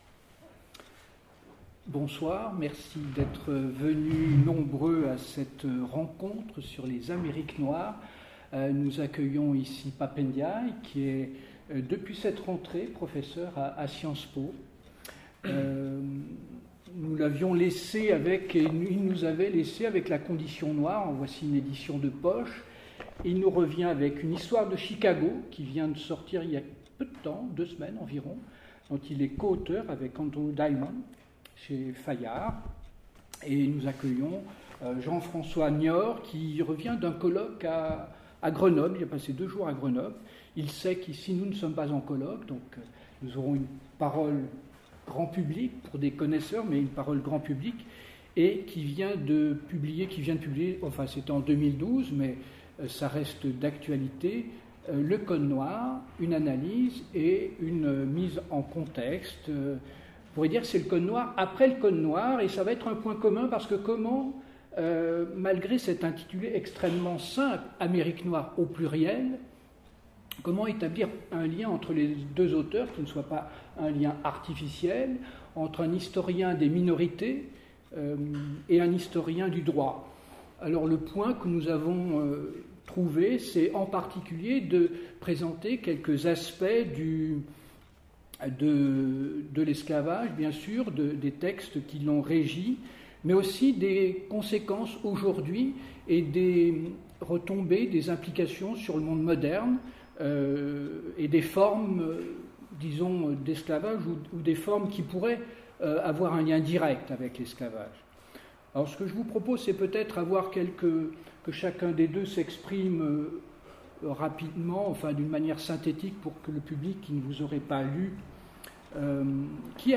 en partenariat avec le Festival des outre-Mers